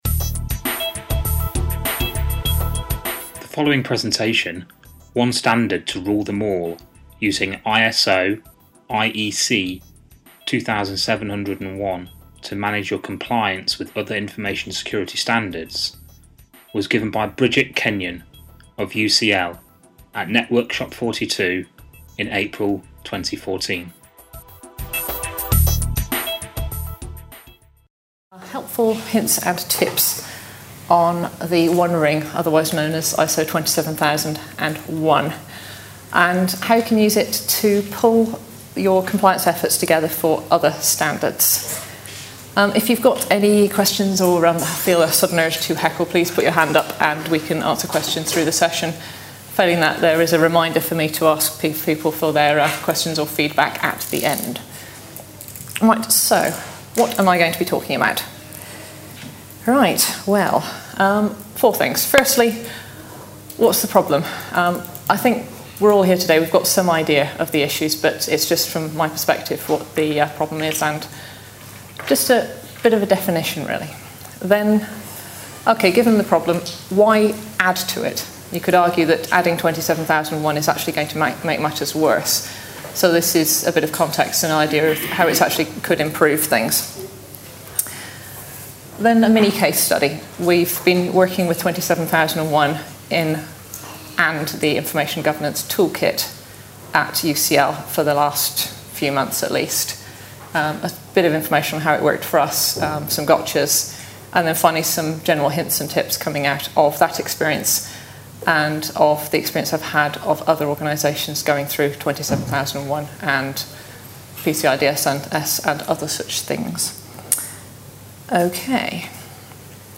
Networkshop 42